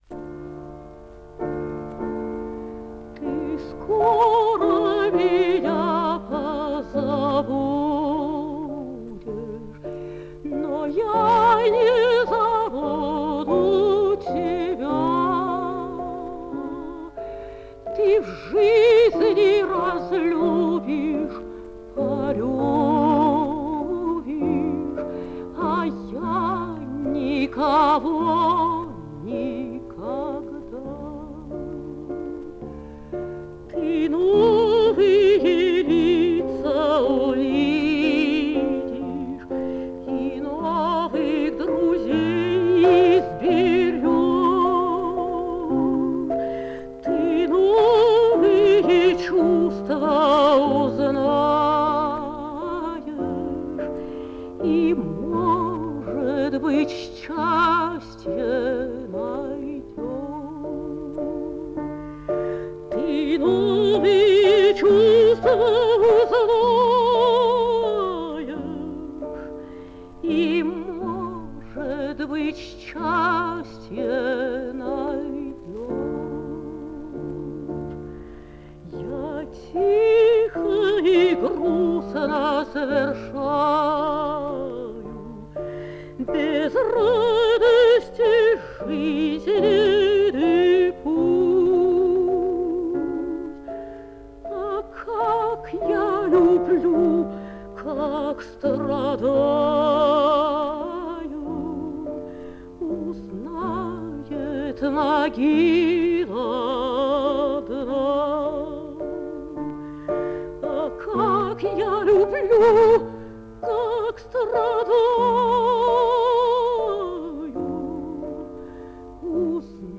(фп.)